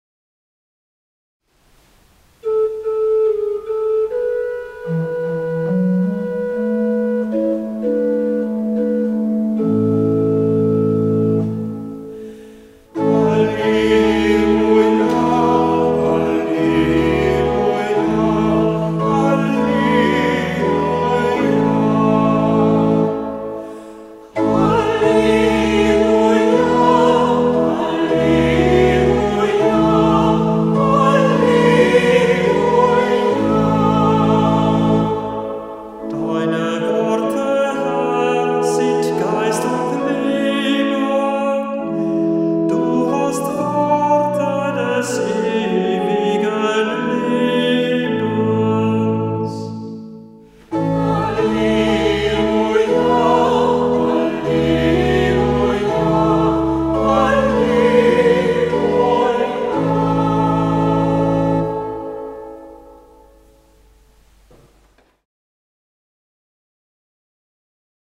Kantor der Verse